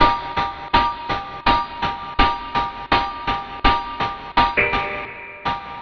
08 Milkmarque 165 G.wav